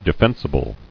[de·fen·si·ble]